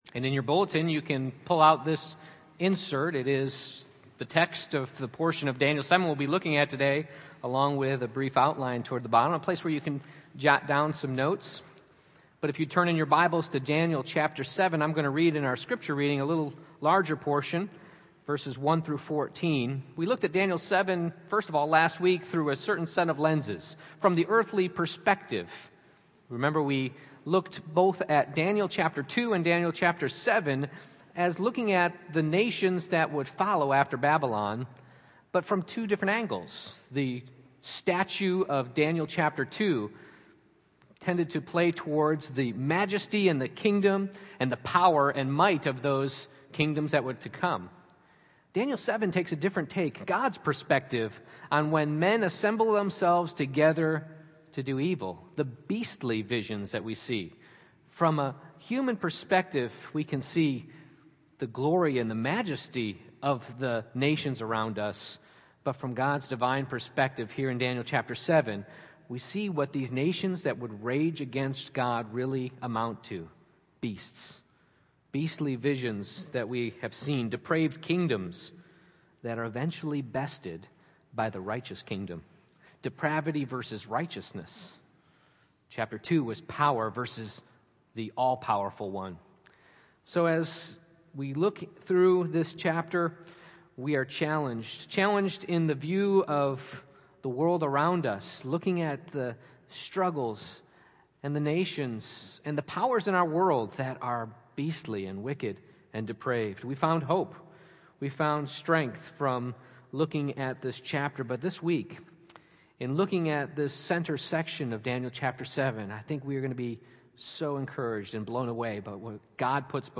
Exposition of Daniel Passage: Daniel 7:1-28 Service Type: Morning Worship « Beastly Visions